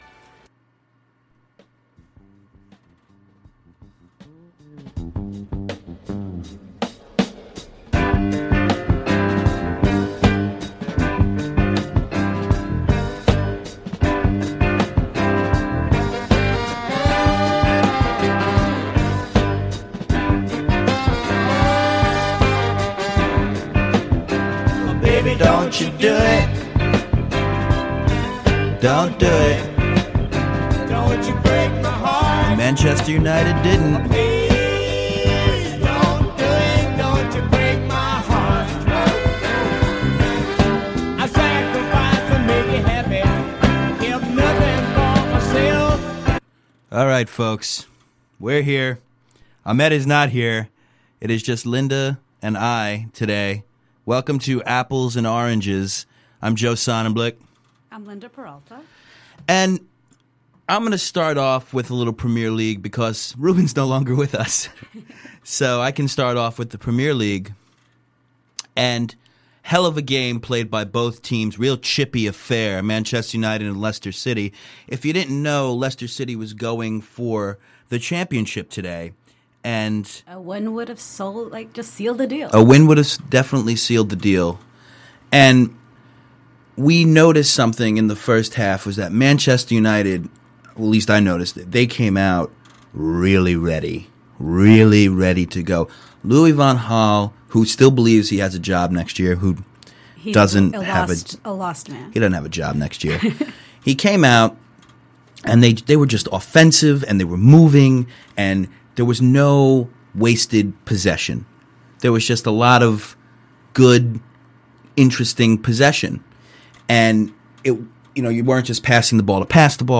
Lots of calls came through.